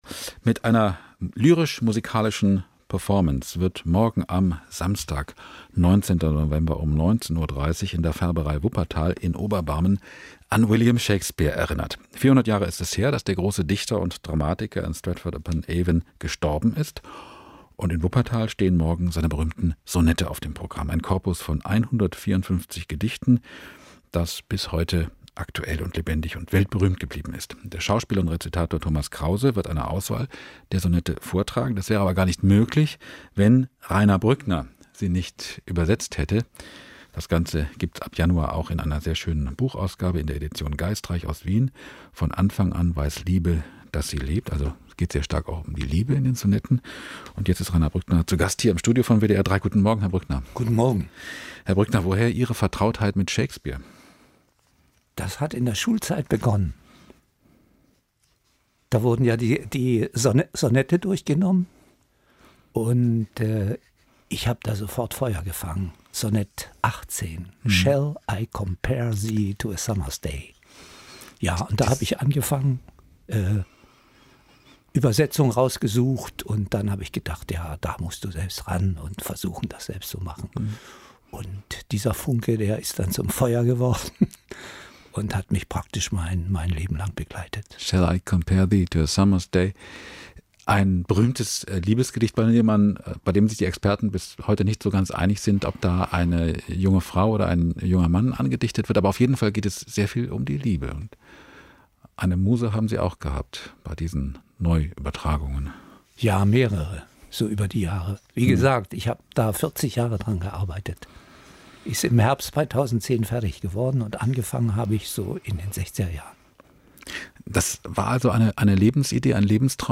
Leseprobe